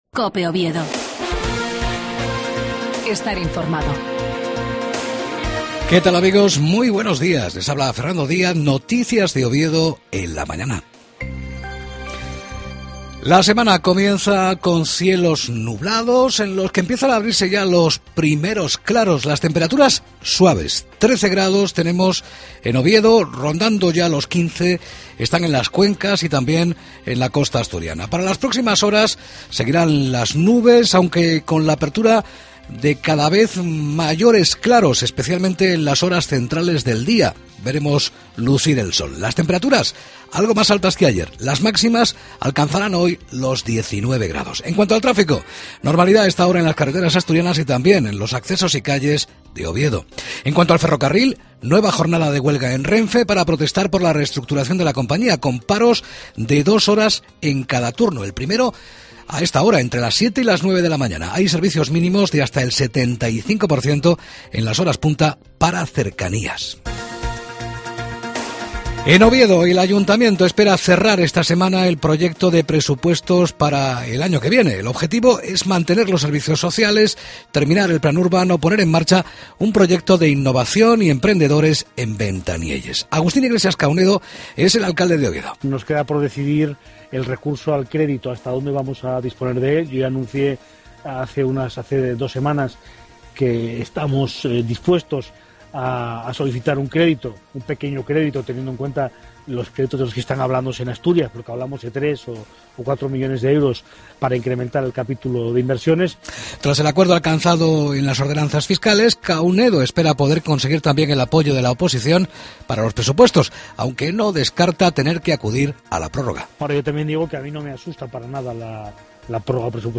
AUDIO: LAS NOTICIAS DE OVIEDO A PRIMERA HORA DE LA MAÑANA.